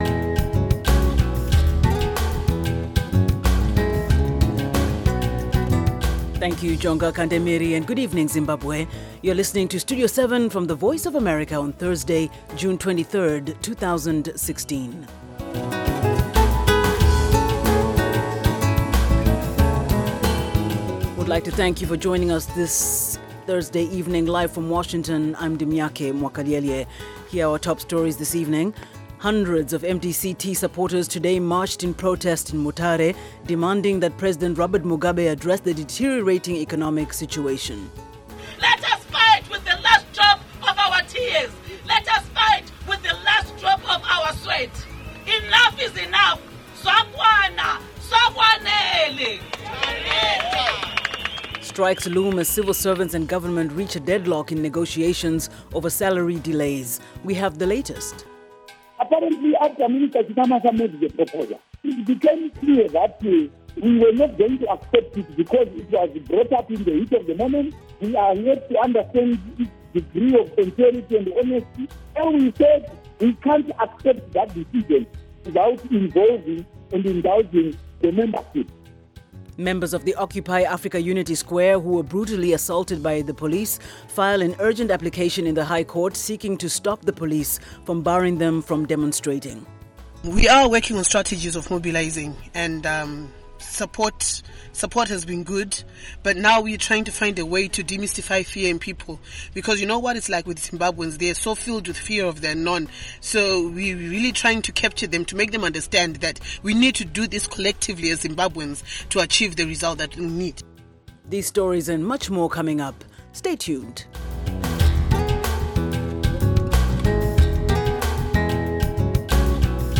Studio 7 News in English